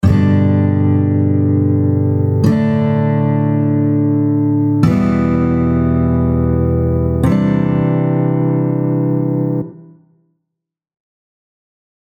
4 Chord progression – G, D5/A, Em, Cadd9.
33-progression-7.mp3